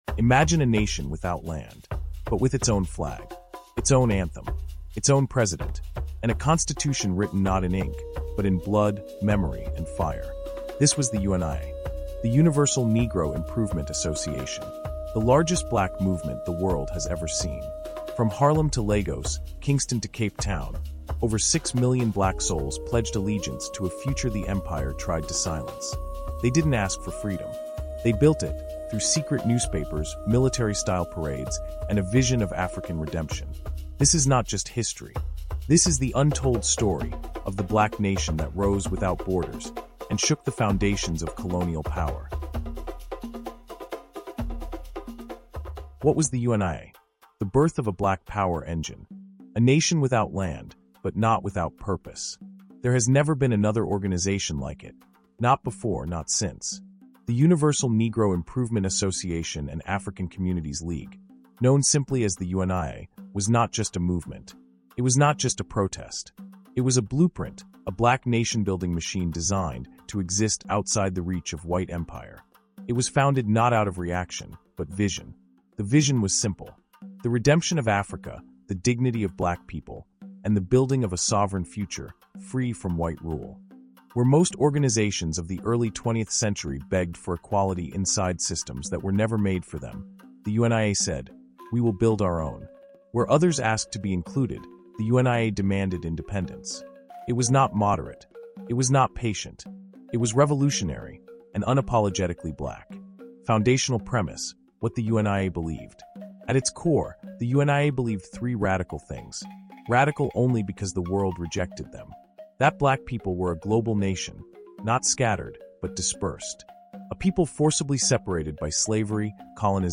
UNIA: The Birth of a Black Power Engine | Audiobook